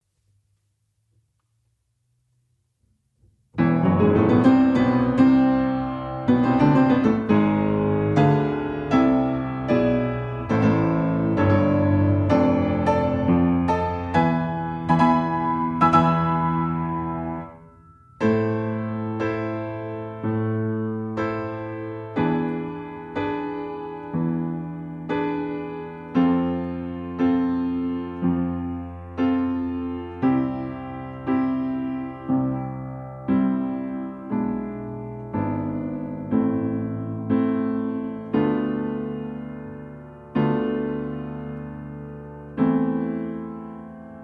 Nagranie bez metronomu, uwzględnia rubata.
II wersja (wolniejsze tempa):
Andante sostenuto: 64 bmp
Nagranie dokonane na pianinie Yamaha P2, strój 440Hz